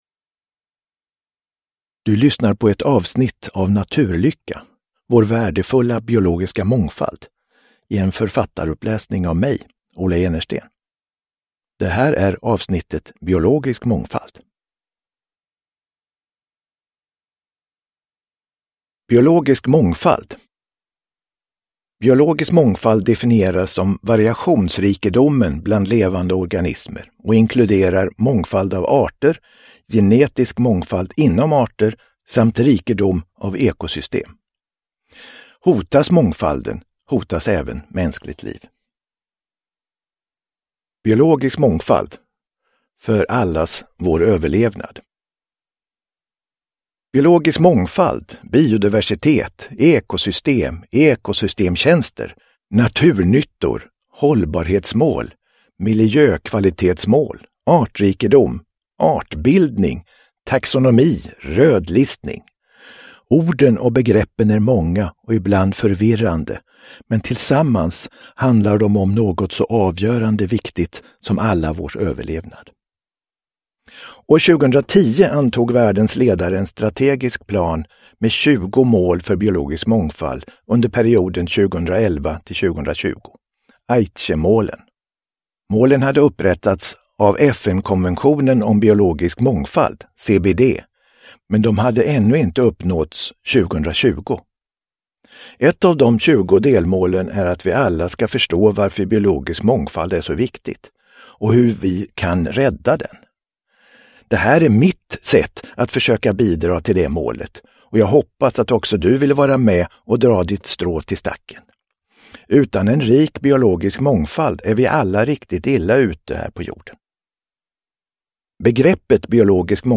Naturlycka - Biologisk mångfald – Ljudbok – Laddas ner